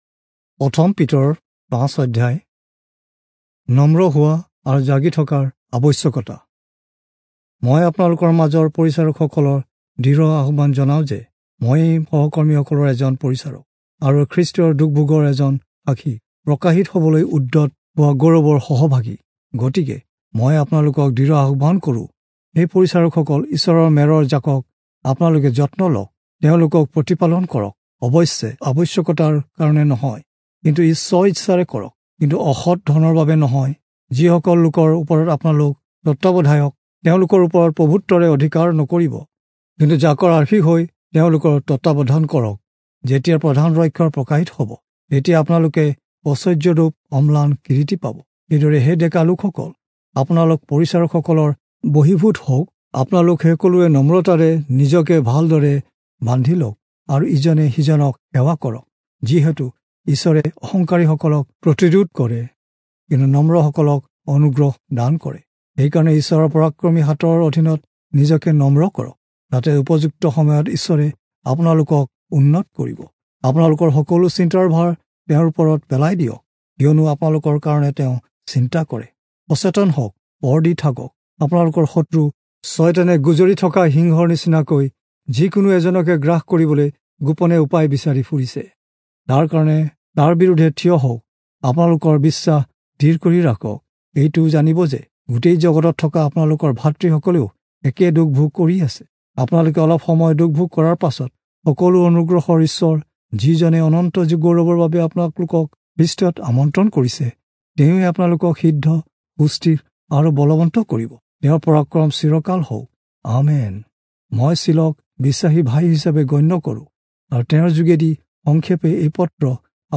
Assamese Audio Bible - 1-Peter 3 in Ervmr bible version